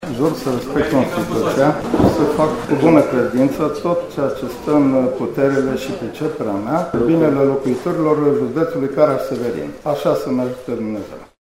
Acesta este din Caransebeş, a mai avut un mandat de consilier şi în plenul ședinței ordinare a forului județean a depus jurământul de credinţă.
juramant-Avram.mp3